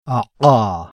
Occlusiva epiglottale